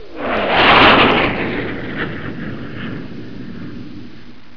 دانلود آهنگ طیاره 52 از افکت صوتی حمل و نقل
دانلود صدای طیاره 52 از ساعد نیوز با لینک مستقیم و کیفیت بالا
جلوه های صوتی